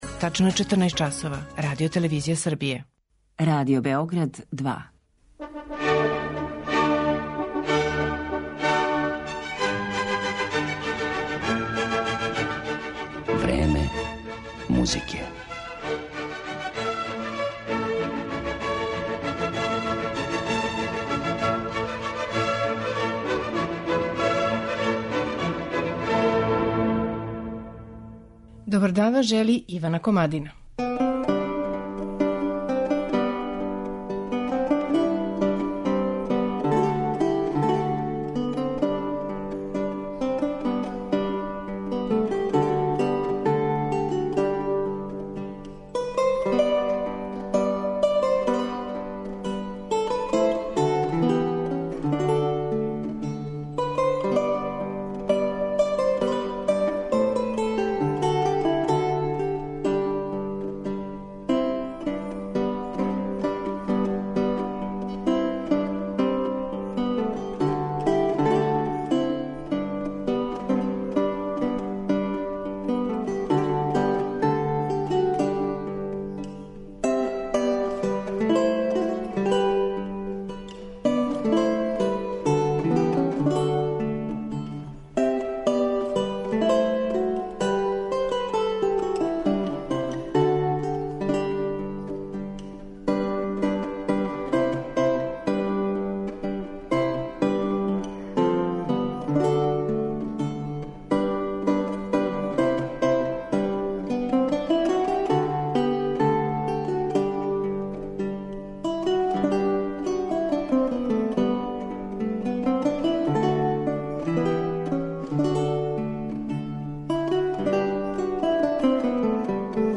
Емисију смо посветили америчком лаутисти Полу Одету
У данашњој емисији Време музике, коју смо посветили Одету, чућете његова тумачења дела ренесансних и барокних мајстора, попут Ђоана Амброзија Далце, Франческа да Милана, Јохана Себастијана Баха, Жана Батисте Бесара, Сантјага де Мурсије...